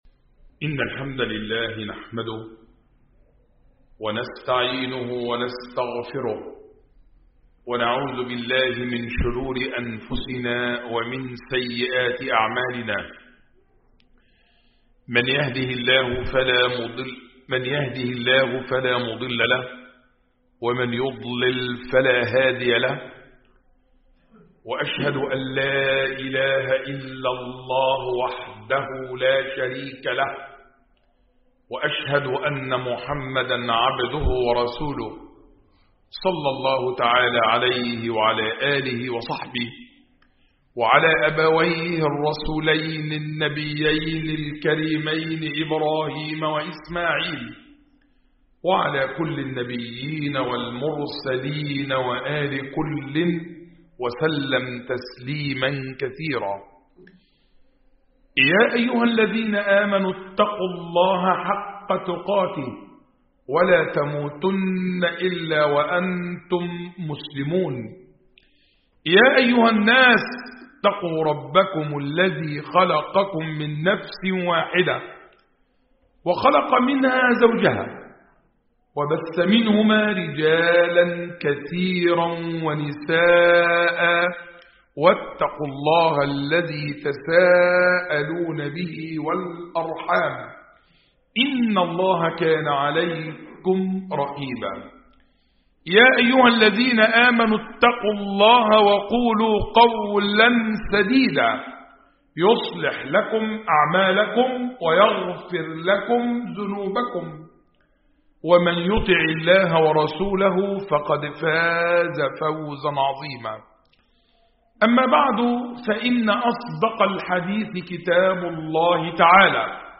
خطبة
خطب الجمعة والأعياد